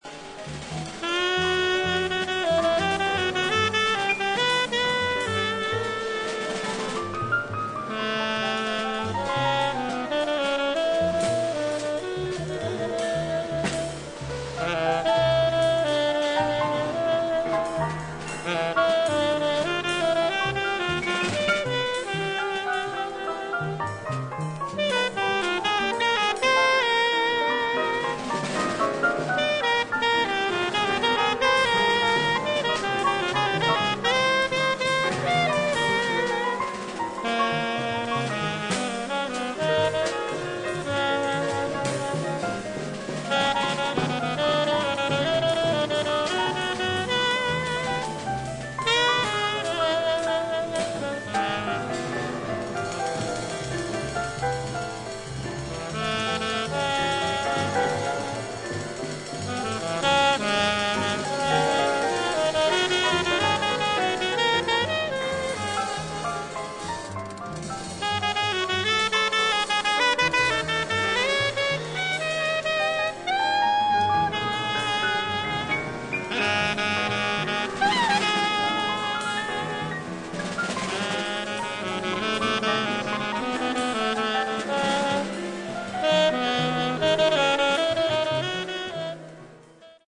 MEDIA：EX ※B1にプレス由来と思われるチリノイズあり。